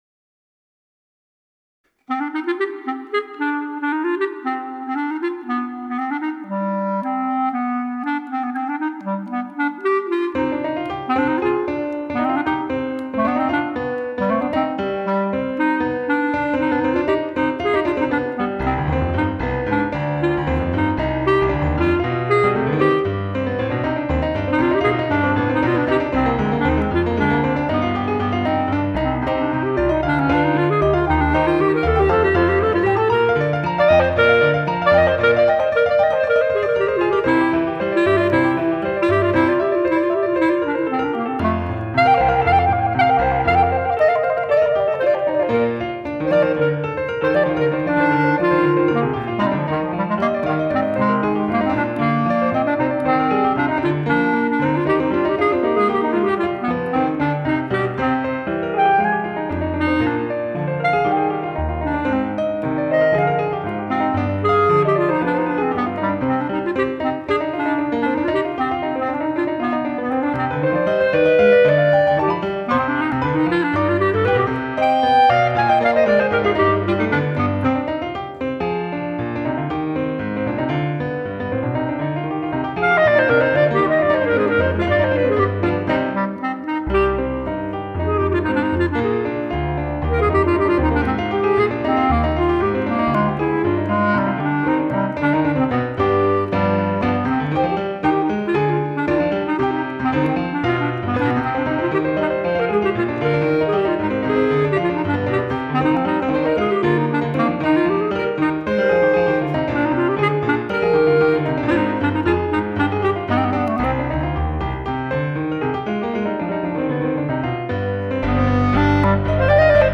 4. A short coda leads the work to its dramatic conclusion.
Range: E1 to C3